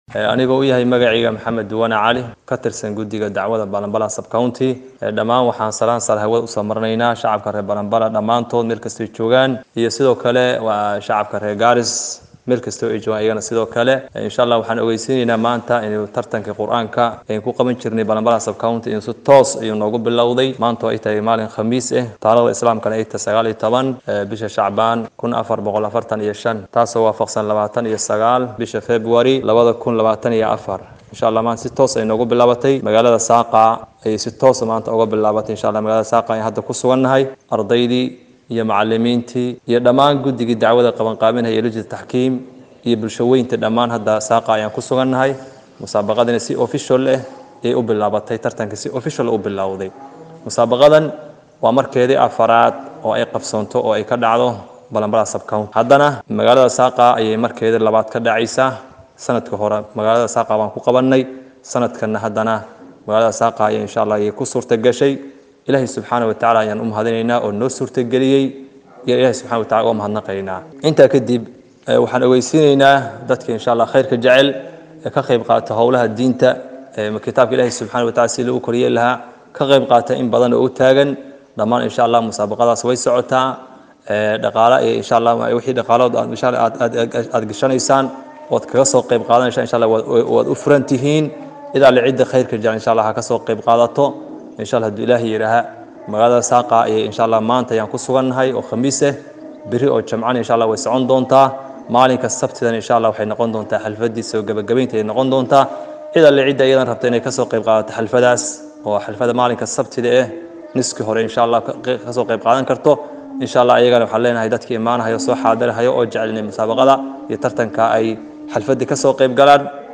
Tartanka ayaa socon doono ilaa Sabtida, waxaana faahfaahinaya xubin ka tirsan guddiga sare ee arrimaha dacwadda ee Balambala.